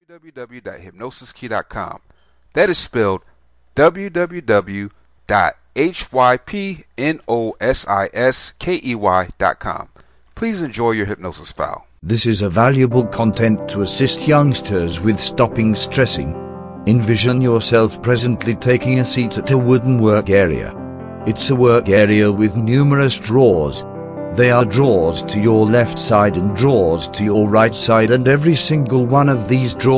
Problem Solving Self Hypnosis Mp3